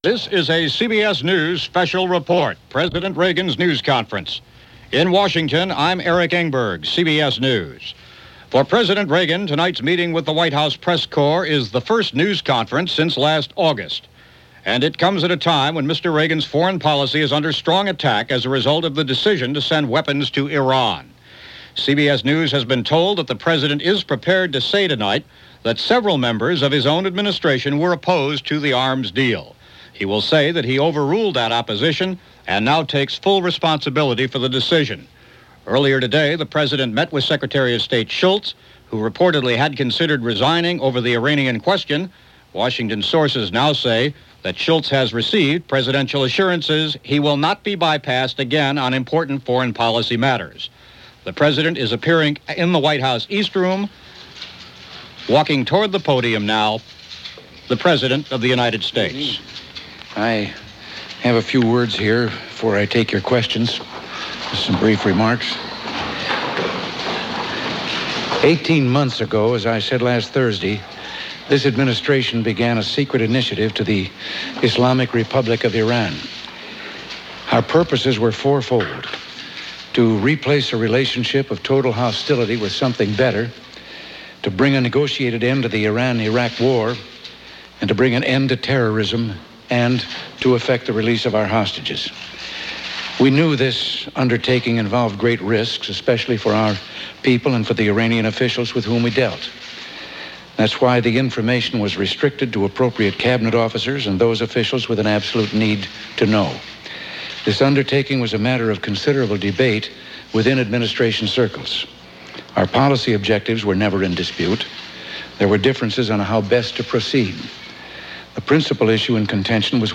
President Reagan News Conference - Iran-Contra: A Box Full Of Surprises - November 19, 1986
November 19, 1986 – As news of illegal arms sales to Iran surfaced, and as questions persisted over who-knew-what/when, it became imperative that President Reagan come forth and at least attempt to explain what was going on. So on this day, President Reagan held his first News conference since August of 1986, in an effort to answer questions and slow the ever-speeding runaway train of allegations, heading ominously towards the dumpster fire of discoveries: